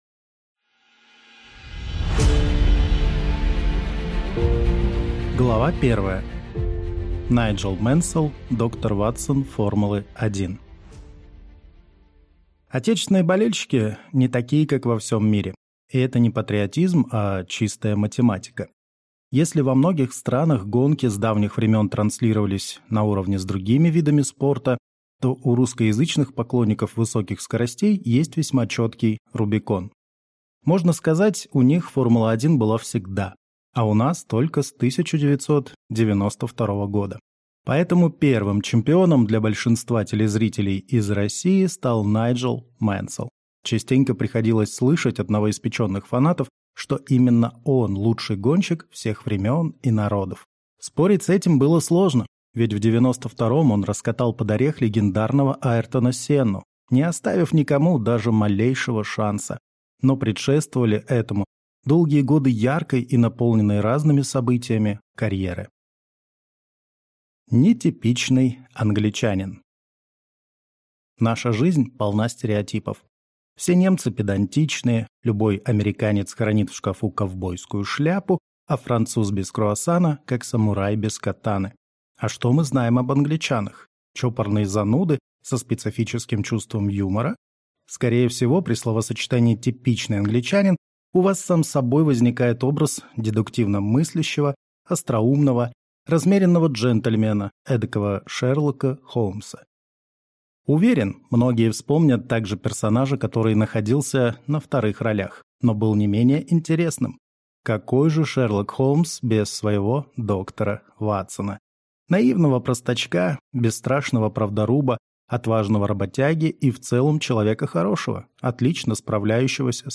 Аудиокнига Самые слики. Хроники «Формулы-1» | Библиотека аудиокниг